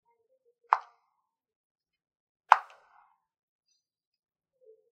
Golpeo de pelota de ping-pong
Grabación sonora en la que se capta el sonido de alguien golpeando con una paleta una pelota de ping-pong
Sonidos: Acciones humanas